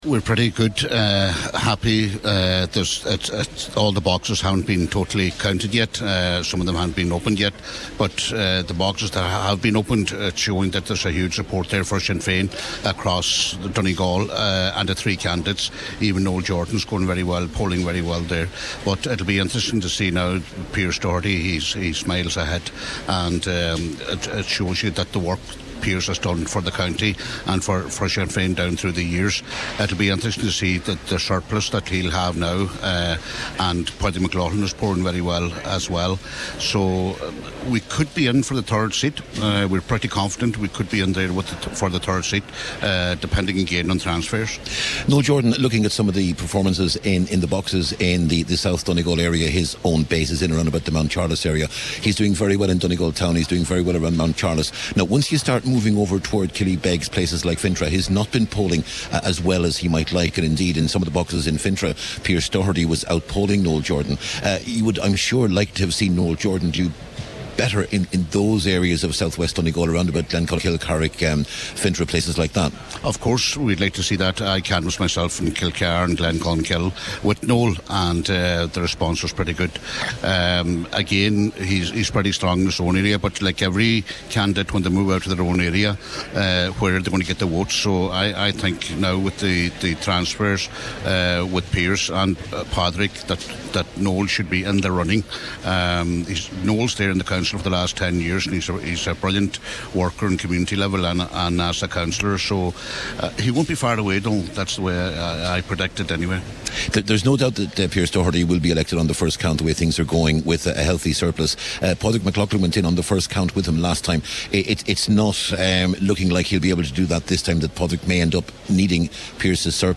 Sinn Fein Councillor John Sheamais O’Fearraigh, Cathaoirleach of the Glenties Municipal District thinks it will be different this time round: